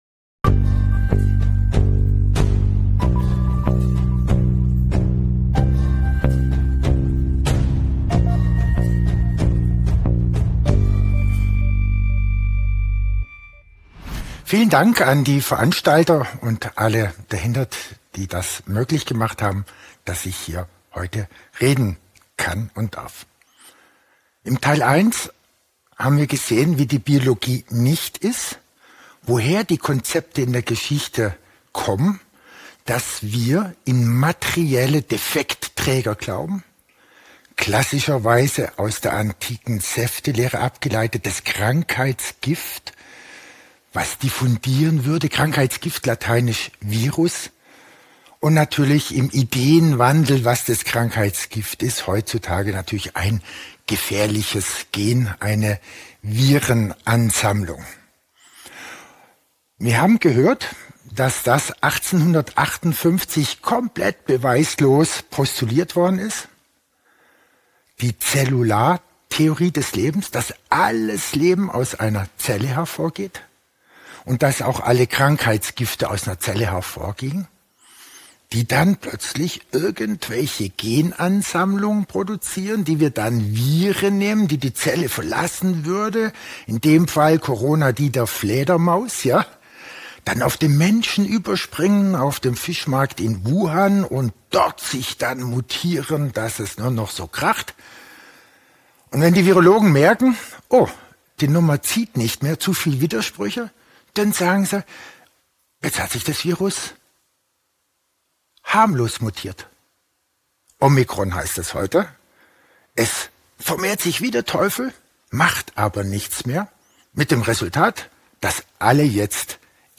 The lecture was recorded on January 21, 2022.